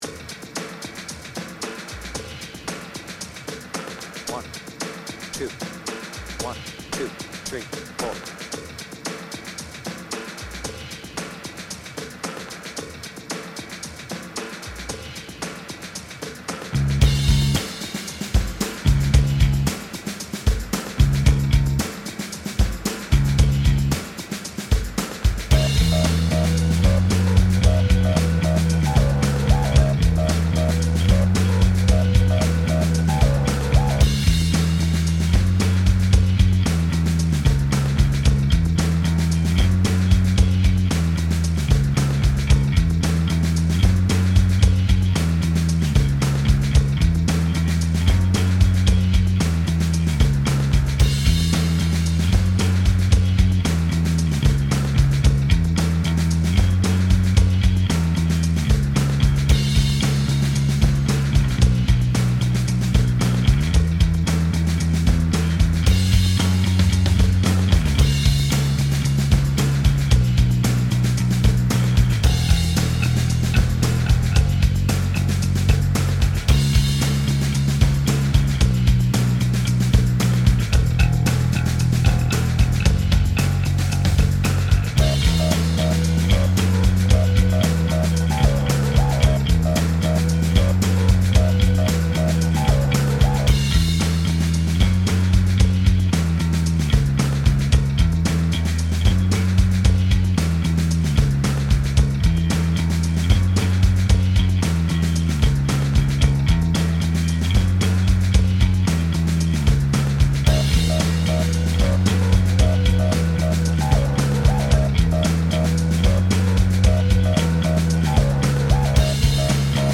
BPM : 113
Tuning : Eb
Without vocals